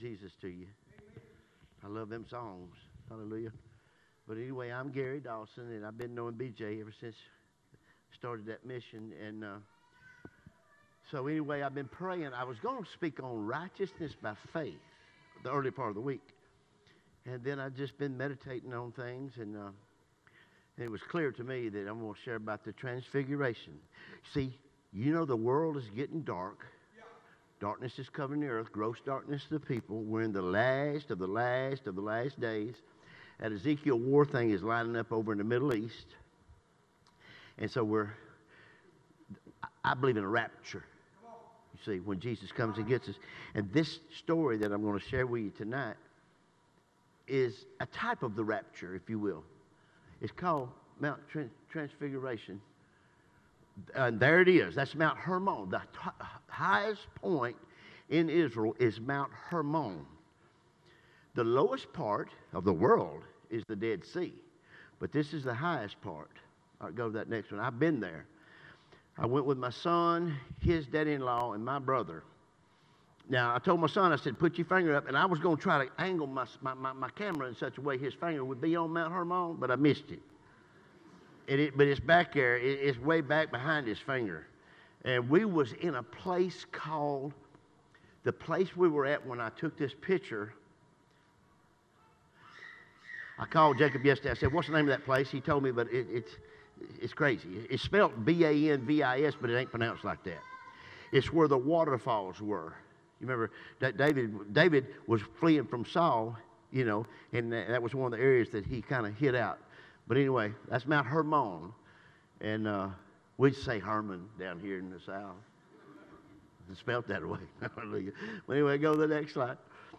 Sermons Archive - Union Chapel Baptist